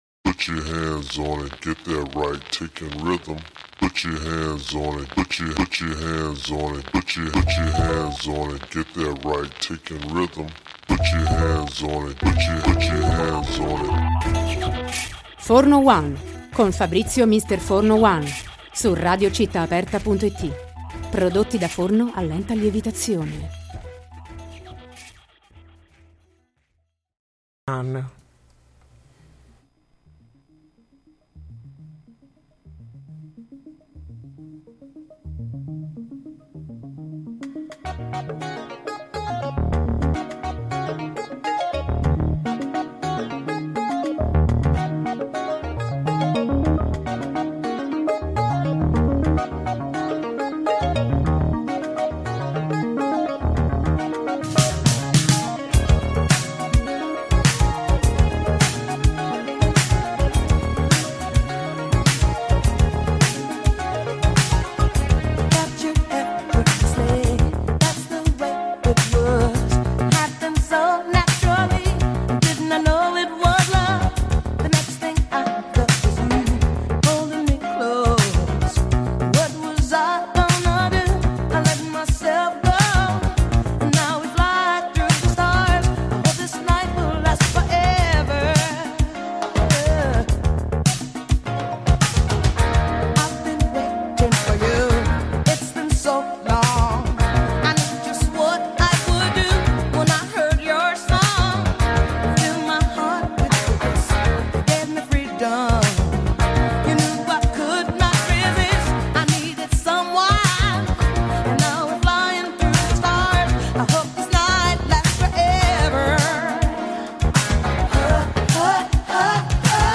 Intervista-Montreux.mp3